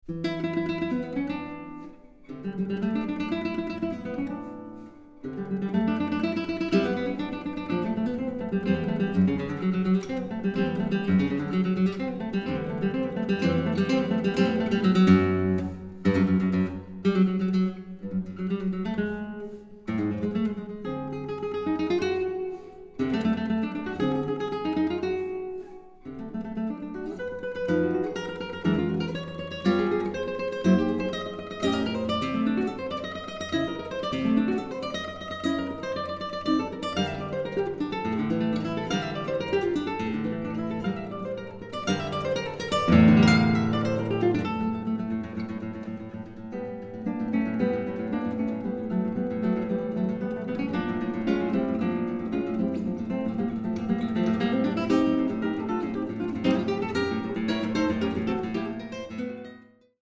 Guitar
richly colored and precisely articulated guitar sound